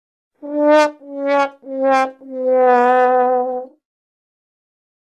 Sad-Trombone.mp3